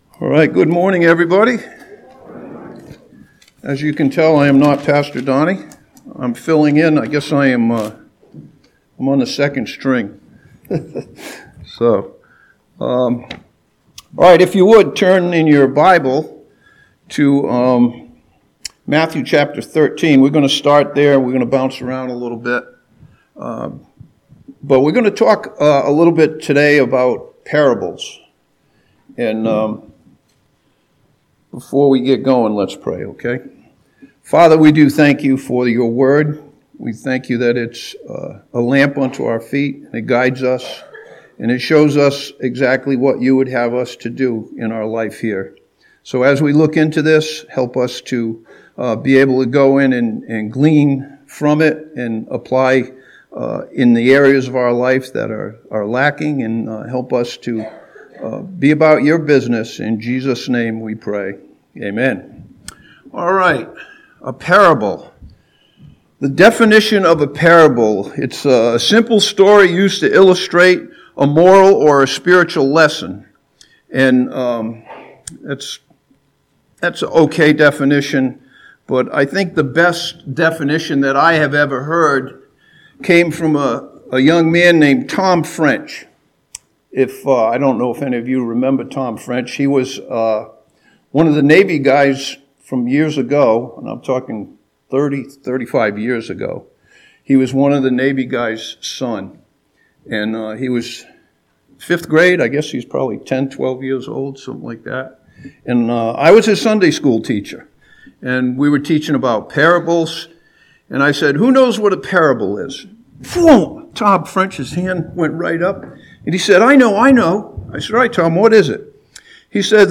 This sermon begins in Matthew chapter 13 and looks at several lessons from the parables of Jesus Christ.